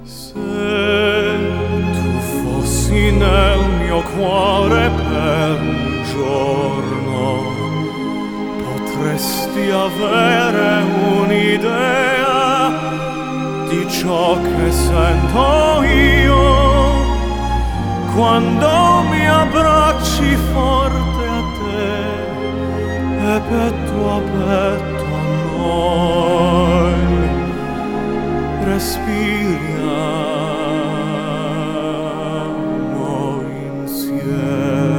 Жанр: Поп музыка / Классика